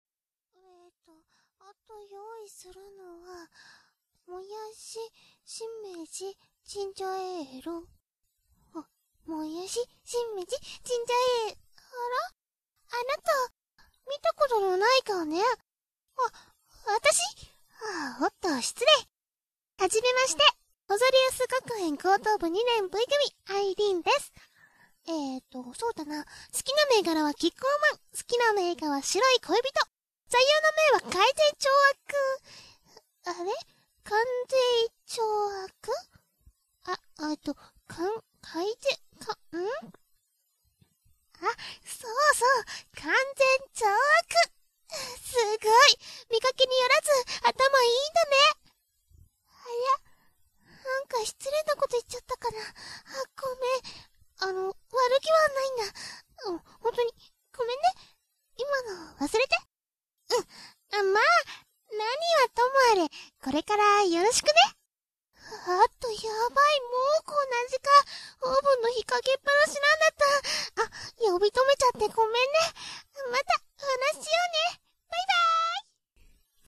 ボイスサンプル>>